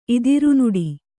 ♪ idirunuḍi